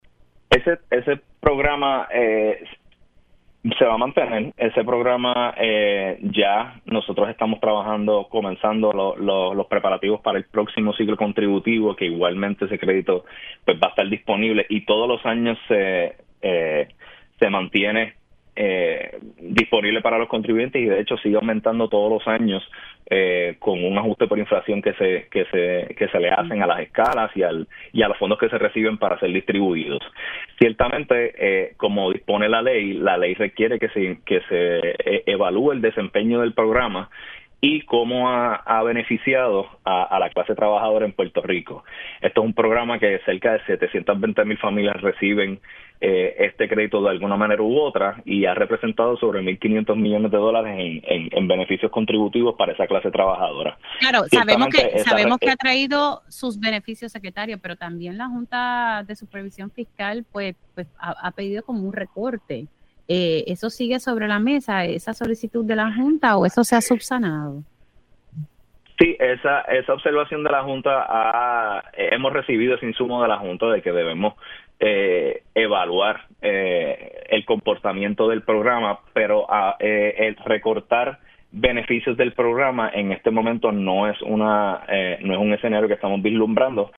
El secretario del Departamento de Hacienda, licenciado Ángel Pantoja, reiteró en Pega’os en la Mañana que no vislumbra que el beneficio del Crédito por Trabajo sea eliminado, luego de que la Junta de Control Fiscal (JCF) anticipara su revisión.